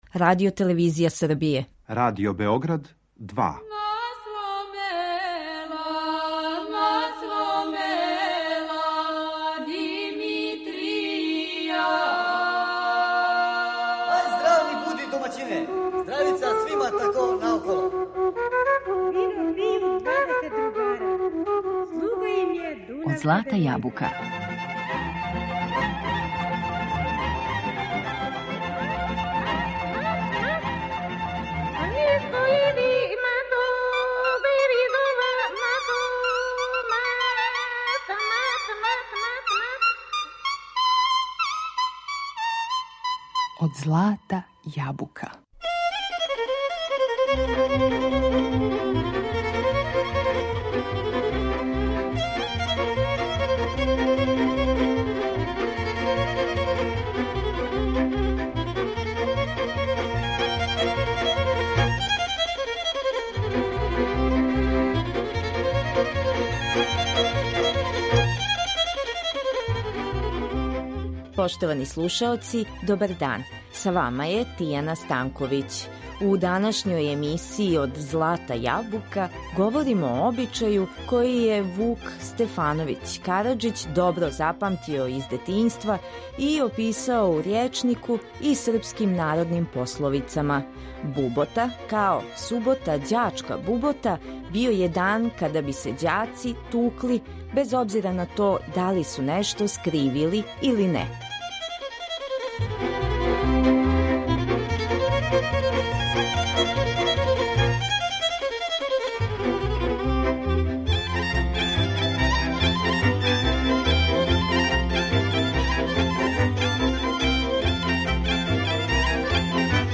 У емисији ћемо споменути и сећања других аутора, уз илустрацију најлепших народних и градских кола у извођењу народних оркестара.